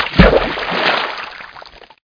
water-splash-1.ogg